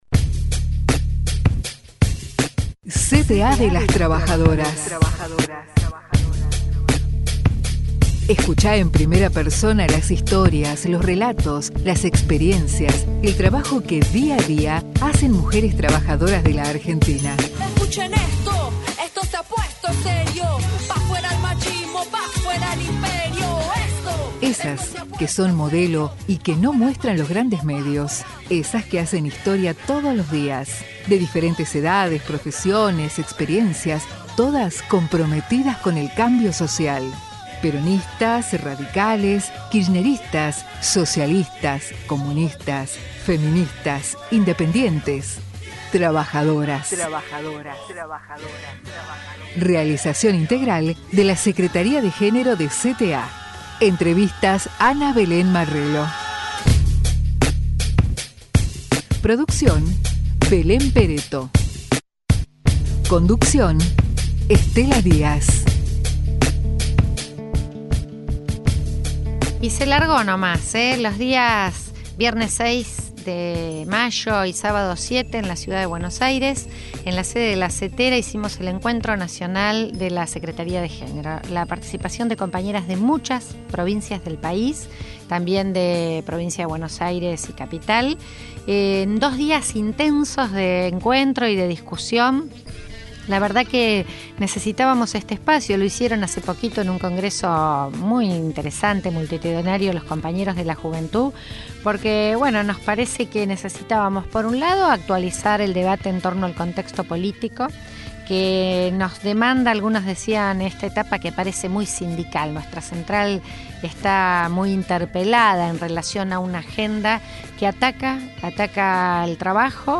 "CTA de las Trabajadoras", el microprograma de la Secretaría de Género de la Central de Trabajadores de la Argentina // CONDUCCIÓN: Estela Díaz.